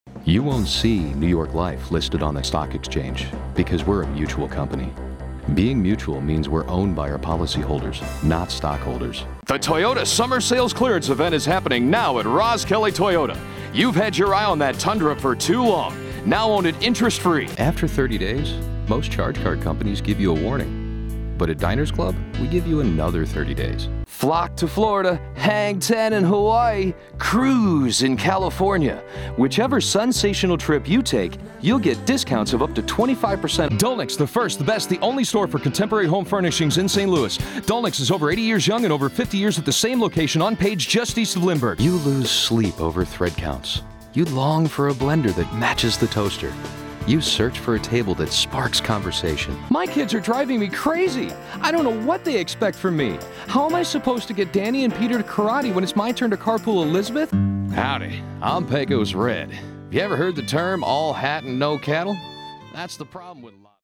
Guy next door voice with a mid 20's to mid 50's. Movie trailer grit, baritone.
middle west
mid-atlantic
Sprechprobe: Werbung (Muttersprache):